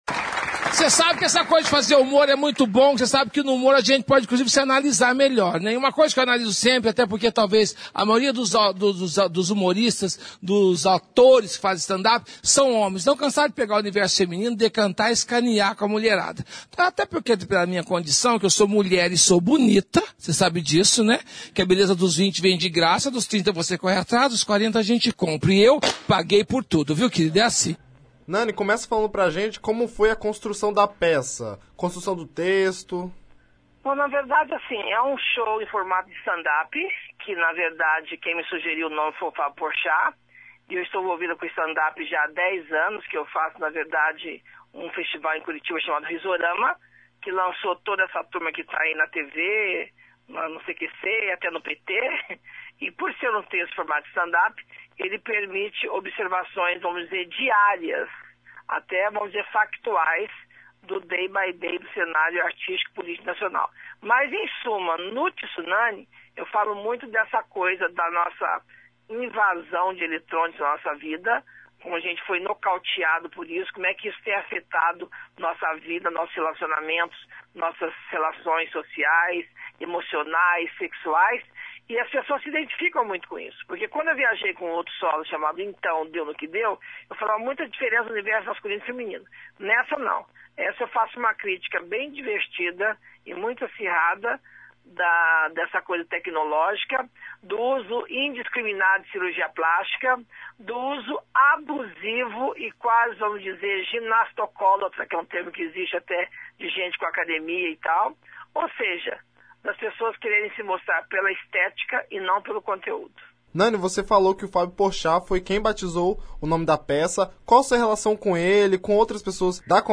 Entrevista com Nany People